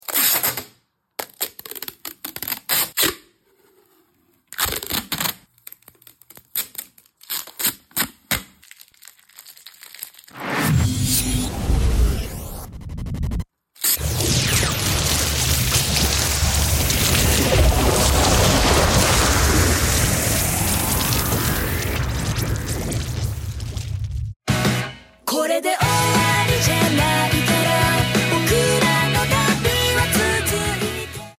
Ever wondered how a simple roll of tape could turn into a Saiyan power-up? Using microphone, I recorded the raw sound of adhesive tape, then transformed it through sound design into an epic energy burst fit for an anime battle!